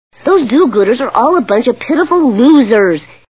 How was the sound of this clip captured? The Simpsons [Bart] Cartoon TV Show Sound Bites